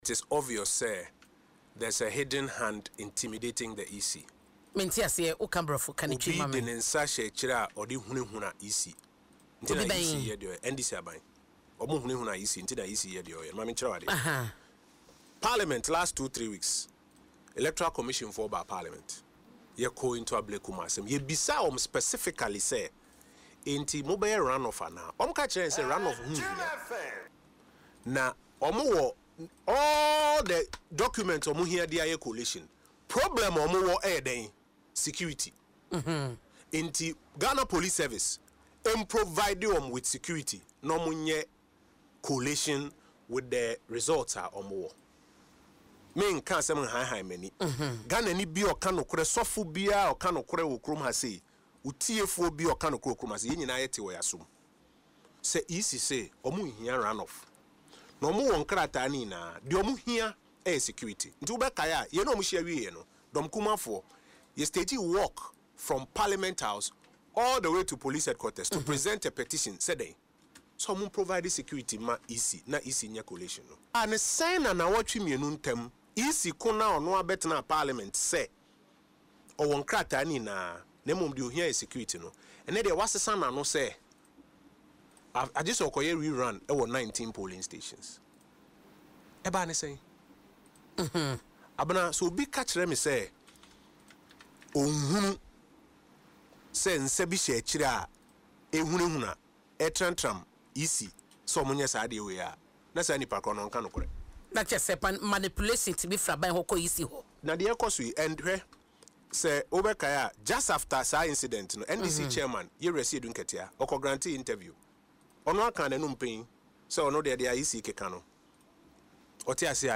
Speaking on Adom FM’s Dwaso Nsem, Mr Oppong Nkrumah claimed the EC’s decision points to political interference, accusing the opposition National Democratic Congress (NDC) of manipulation.